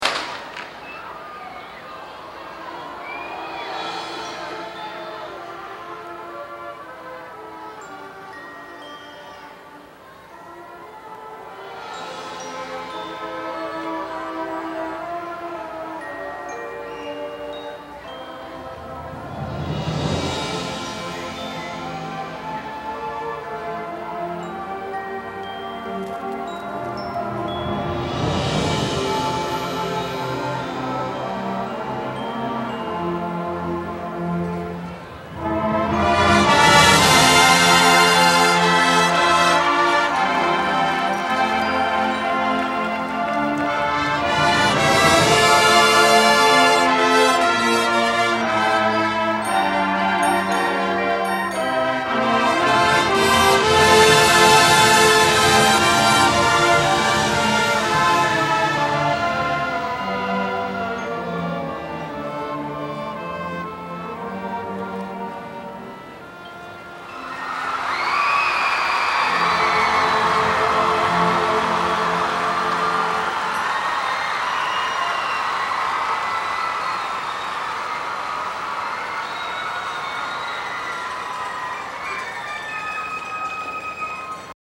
Performed by the marching band in the 1993 “Irish” field show, winning both the TOB Chapter VI Championships and the TOB Atlantic Coast Championships.
danny-boy-chs-band-1993-acc-championships.mp3